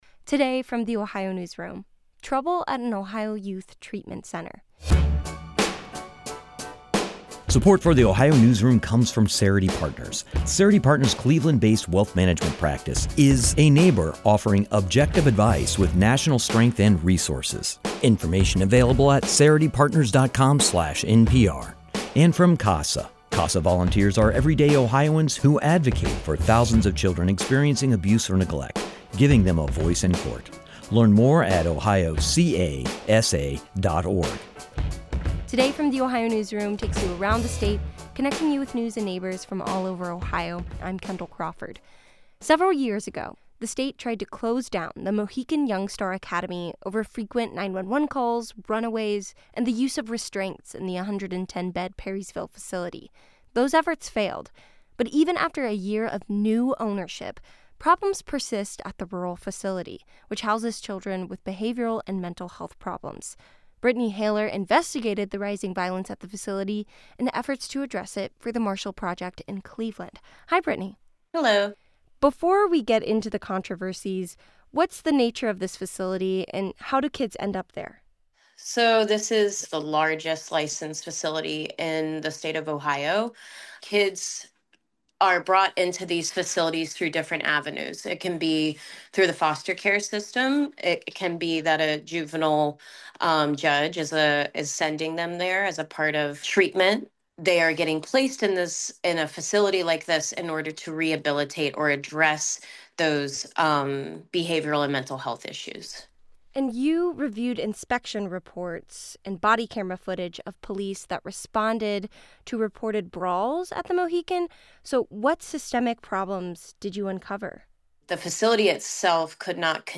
She sat down for an interview with The Ohio Newsroom.